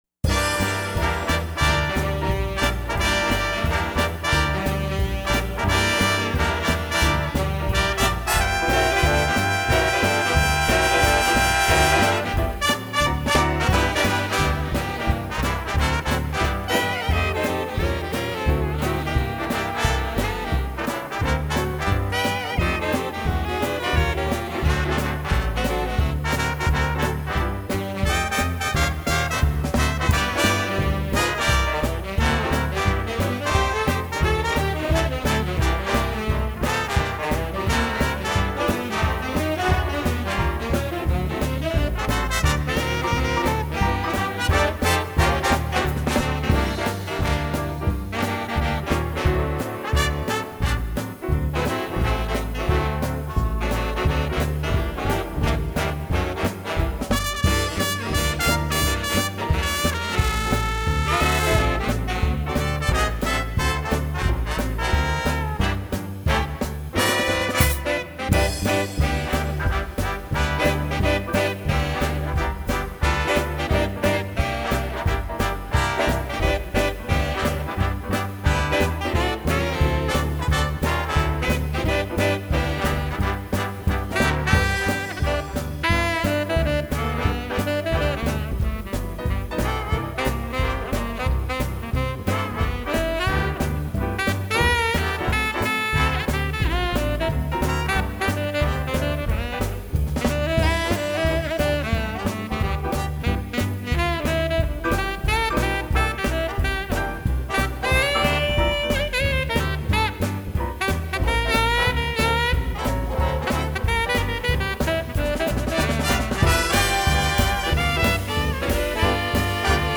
top music from the 20’s through the 40’s.
Big Band and Swing music